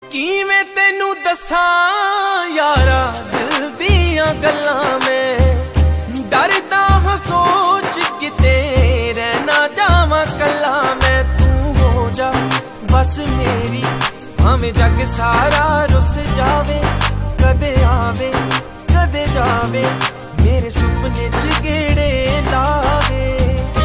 Punjabi music